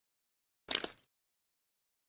Tiếng xịt sốt tương Cà, tương Ớt, sốt gia vị… (Mẫu số 3)
Thể loại: Tiếng ăn uống
tieng-xit-sot-tuong-ca-tuong-ot-sot-gia-vi-mau-so-3-www_tiengdong_com.mp3